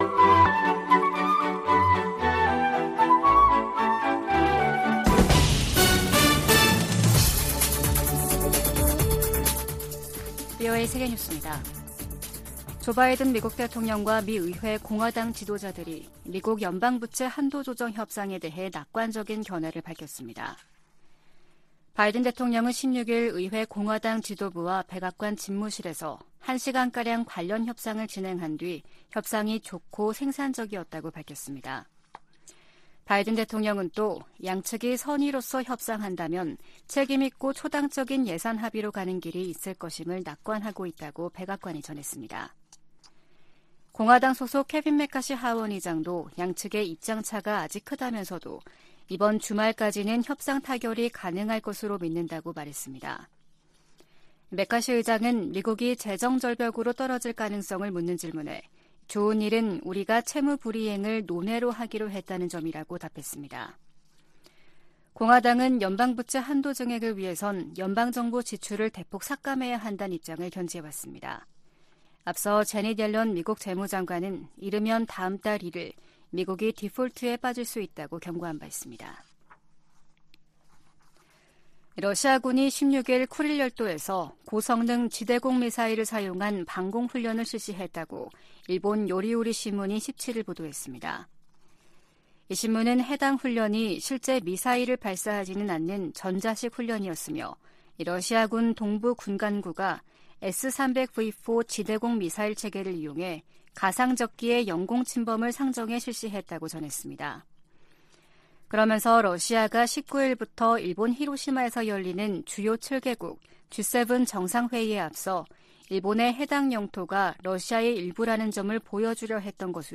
VOA 한국어 아침 뉴스 프로그램 '워싱턴 뉴스 광장' 2023년 5월 18일 방송입니다. 로이드 오스틴 미 국방장관은 상원 청문회에서 한국에 대한 확장억제 강화조치를 취하는 중이라고 밝혔습니다. 북한은 우주발사체에 위성 탑재 준비를 마무리했고 김정은 위원장이 '차후 행동계획'을 승인했다고 관영매체들이 전했습니다. 미 국무부가 화학무기금지협약(CWC) 평가회의를 맞아 북한이 생화학무기 프로그램을 보유하고 있다는 평가를 재확인했습니다.